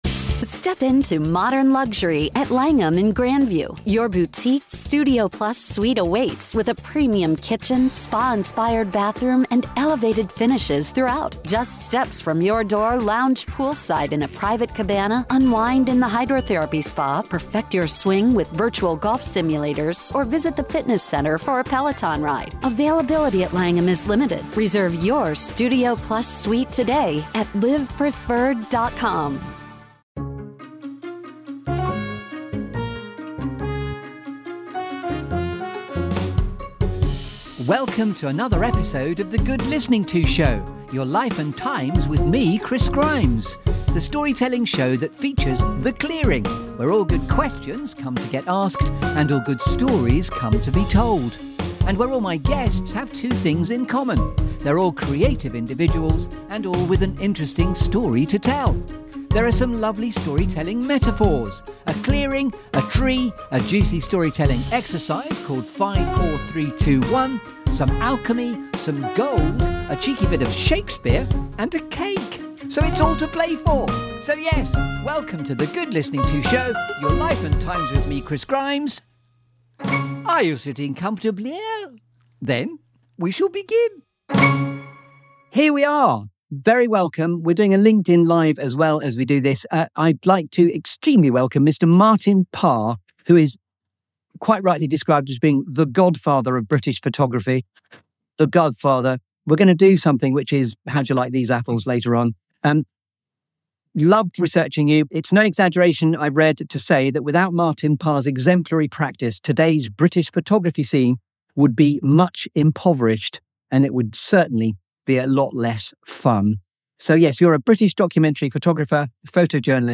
This feel-good Storytelling Show that brings you ‘The Clearing’.